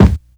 • Mid Heavy Kick D Key 291.wav
Royality free kick drum single hit tuned to the D note. Loudest frequency: 276Hz
mid-heavy-kick-d-key-291-nNV.wav